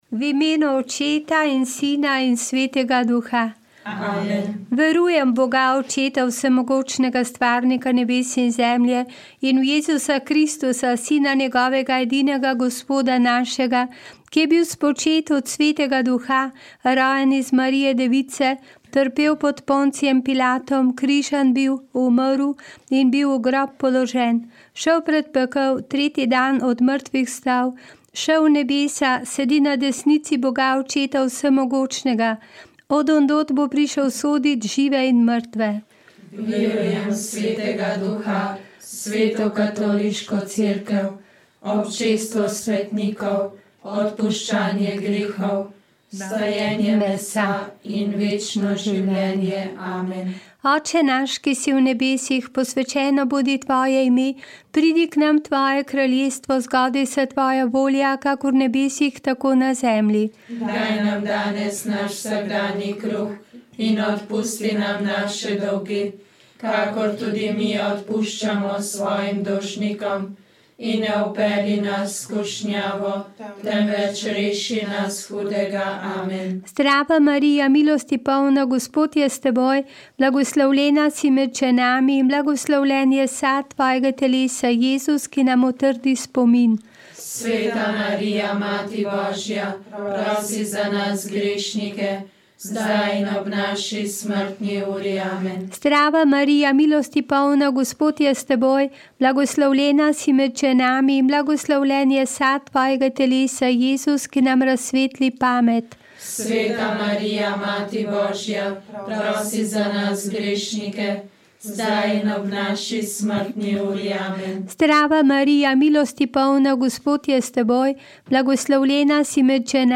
4. misijonski pogovorni večer: »Hrani moja jagnjeta!«